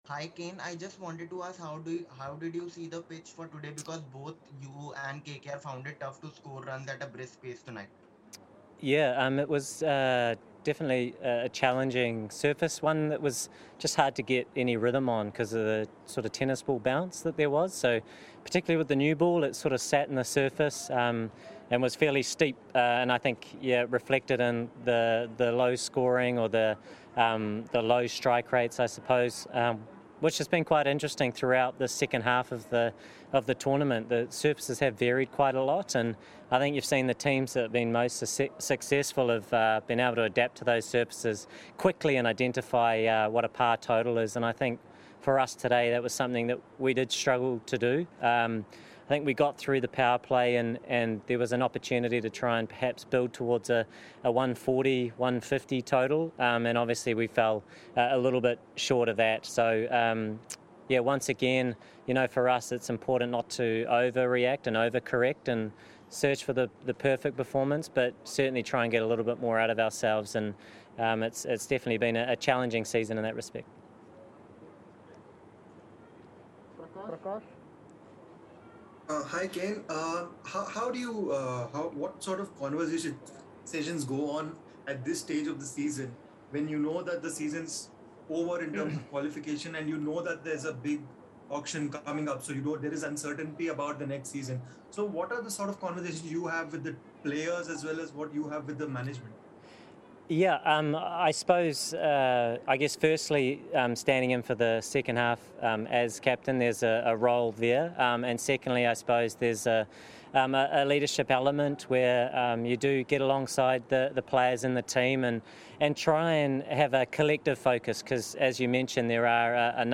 Kane Williamson of Sunrisers Hyderabad addressed the media at the end of the game